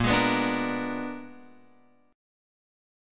A#7b9 chord